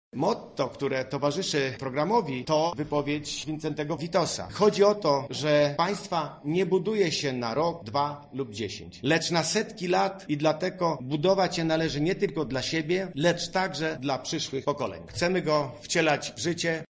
Nasz program jest prosty – mówi poseł Jan Łopata.